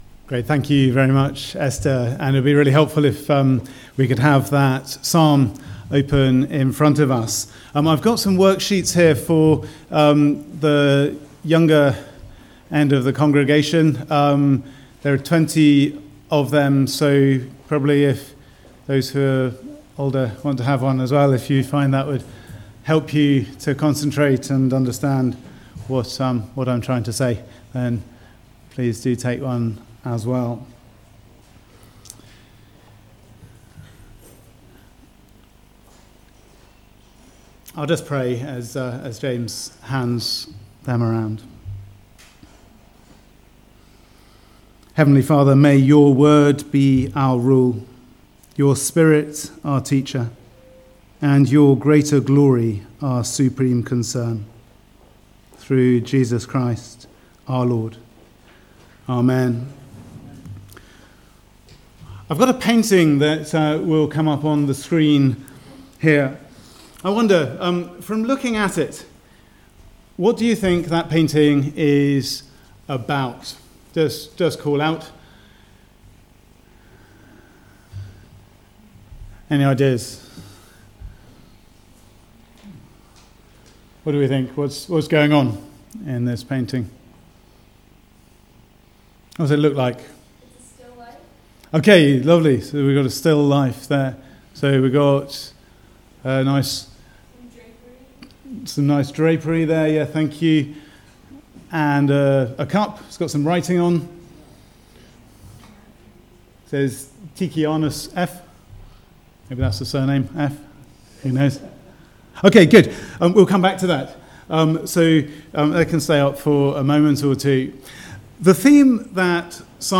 Psalm 73 Service Type: Weekly Service at 4pm « Jonah